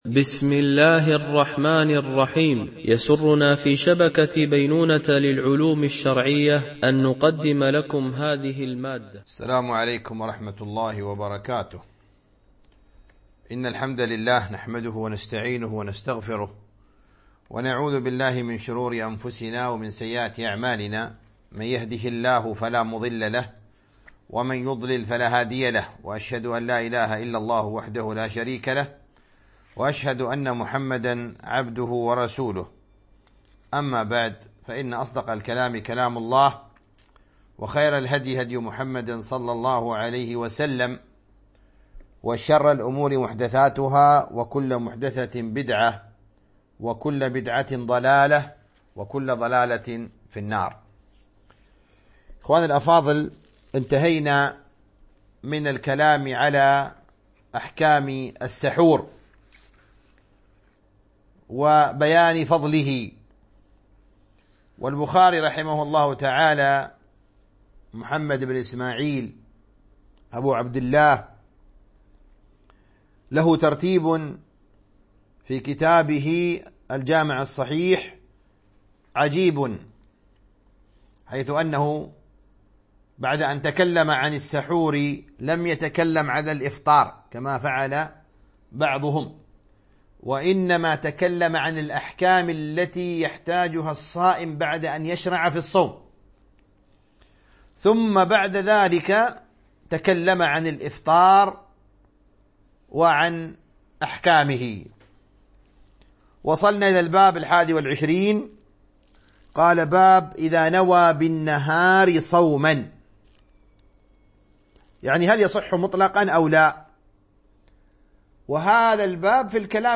التعليق على كتاب الصيام من صحيح البخاري ـ الدرس 6